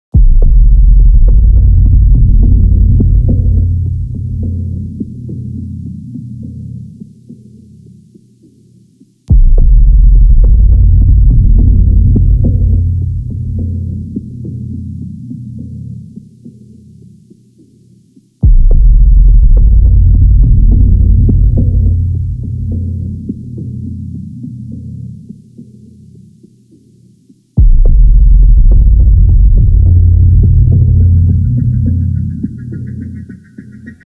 This page was seemingly empty - aside from an ominous drum beat...